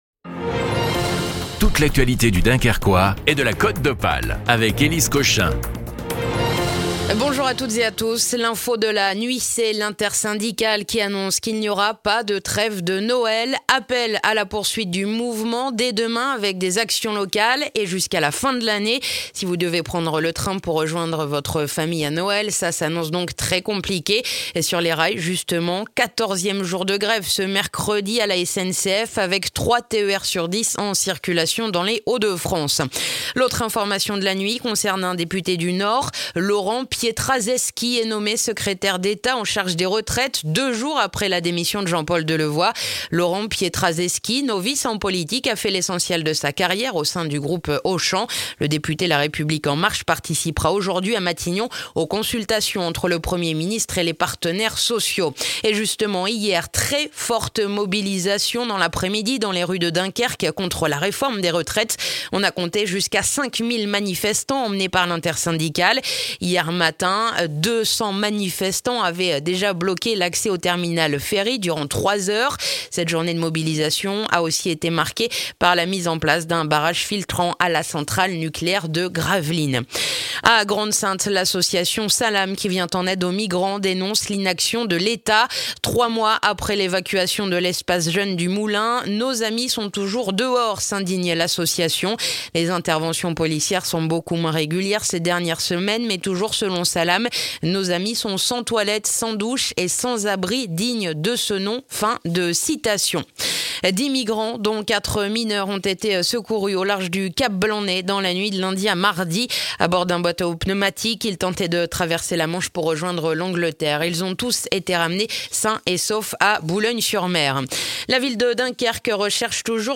Le journal du mercredi 18 décembre dans le dunkerquois